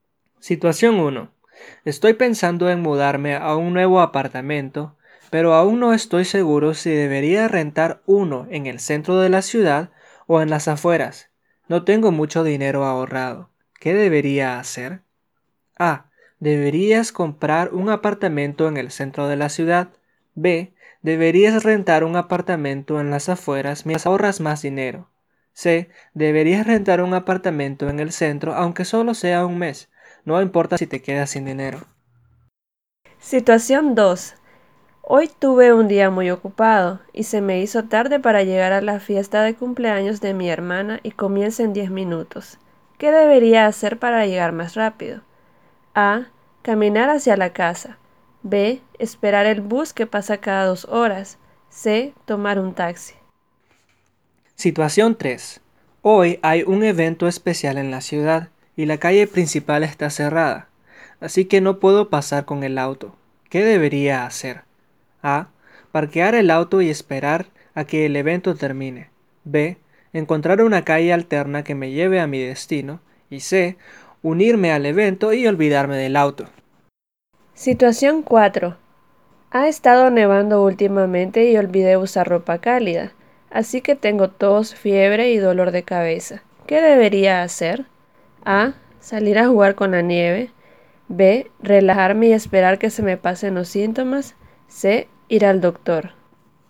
Hola a todos, En este segundo episodio vamos a escuchar una conversación de Objetos perdido en español, repitela y practicala tantas veces como sea necesario.